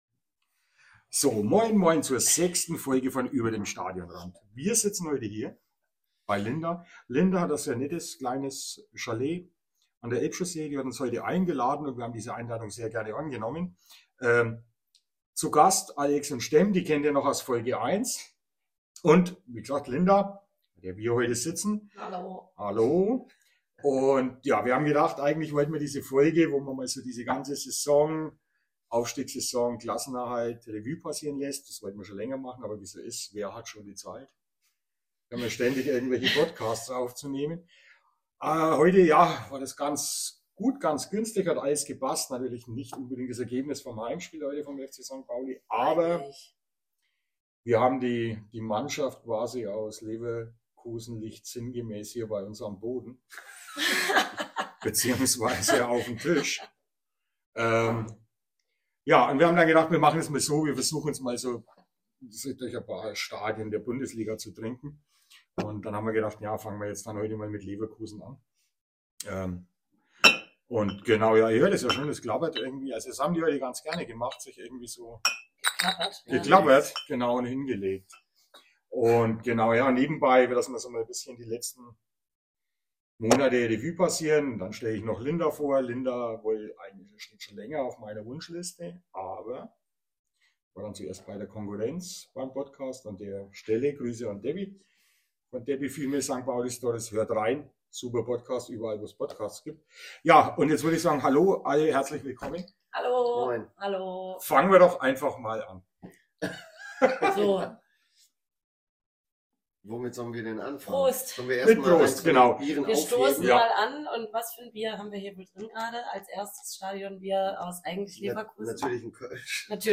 Es entstand ein leicht chaotisches, aber schwer amüsantes Hörvergnügen mit satter Nachspielzeit plus unangemeldetem Besuch der Verwandtschaft!:) Vorab noch eine kurze Anmerkung, respektive Triggerwarnung: In dieser Folge werden alkoholische Getränke konsumiert, der Konsum aber weder glorifiziert, noch heruntergespielt.
Ach….es gab einige technische Schwierigkeiten bis hin zum Ausfallen der Mikros!